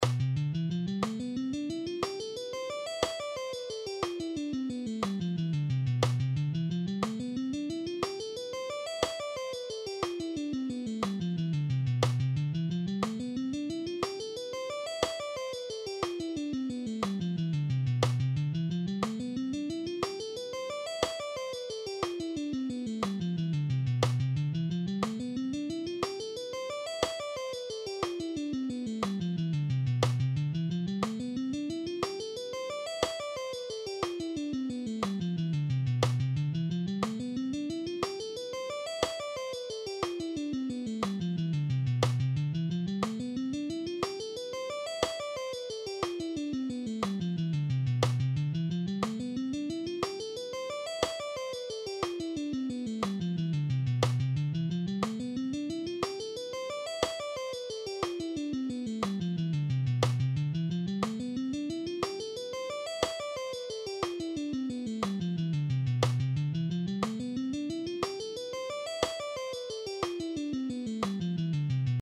All these guitar exercises are in ‘C’
Locrian Finger Picking Guitar Lesson
7.-Locrian-Finger-Picking-Guitar-Lesson.mp3